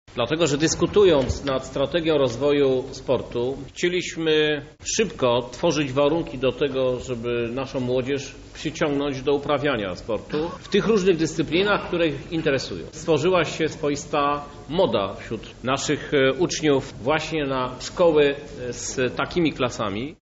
-To jest niewątpliwie powód do dumy – zauważa Krzysztof Żuk, prezydent Lublina.